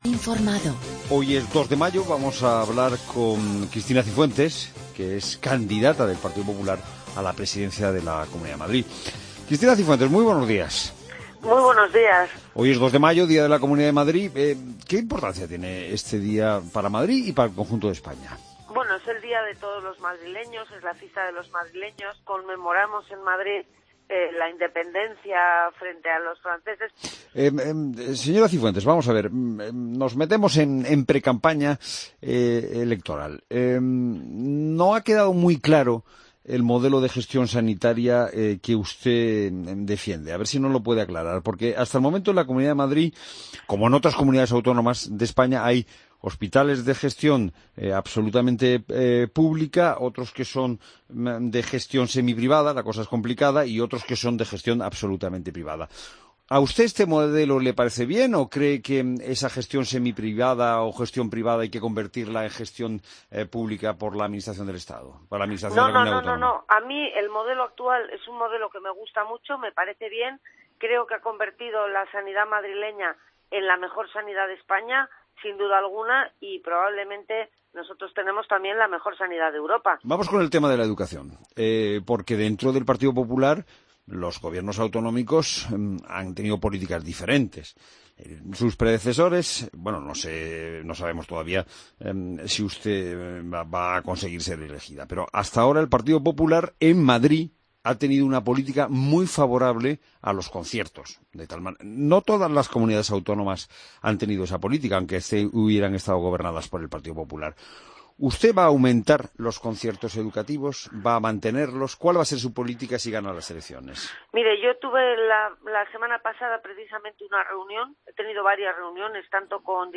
Escucha la entrevista a Cristina Cifuentes en La Mañana de Fin de Semana